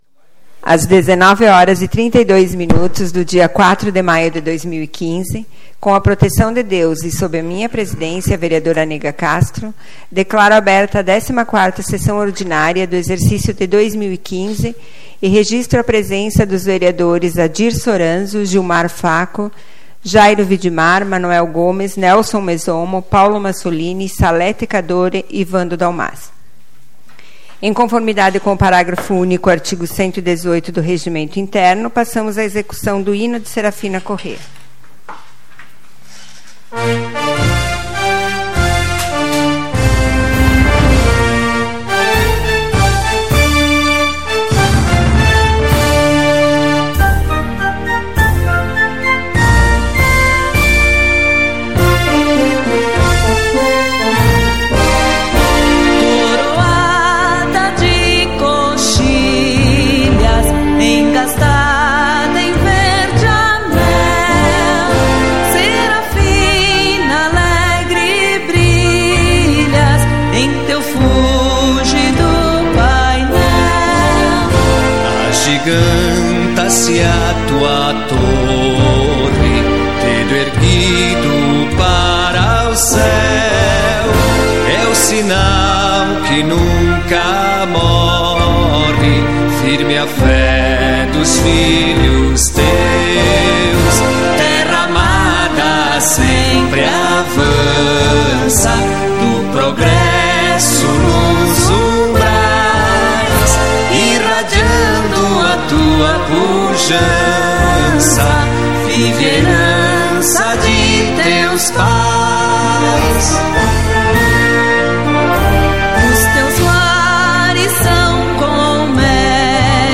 Leitura da Ata nº 15/2015, da 13ª Sessão Ordinária, de 27 de abril de 2015.